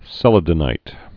(sĕlə-dn-īt)